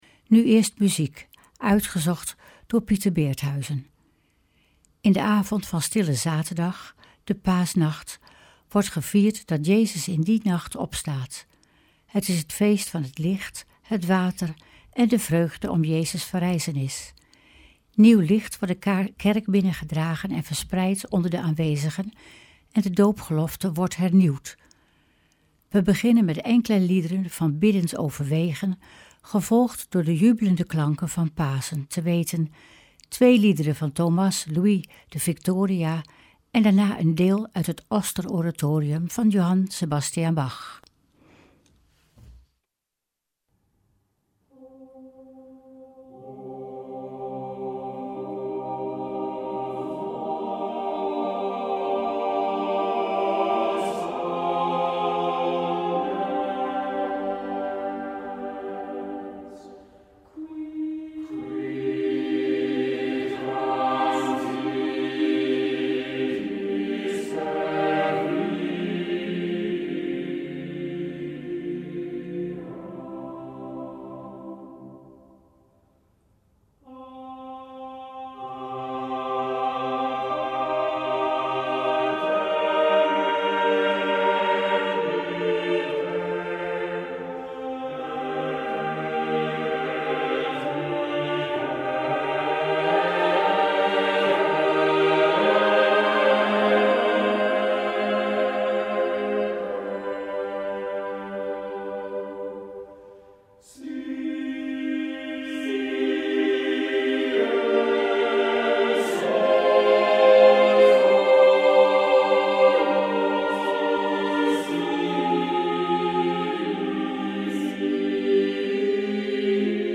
Opening van deze Stille Zaterdag, Paasnacht met muziek, rechtstreeks vanuit onze studio.